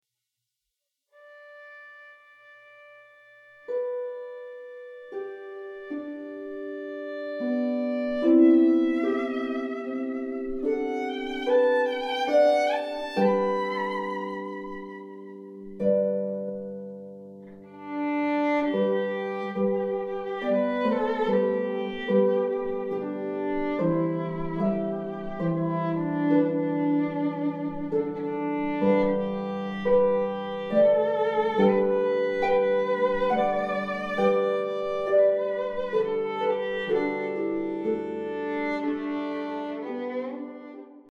violin and piano (or harp)
traditional American lullaby
dance like rhythms and melody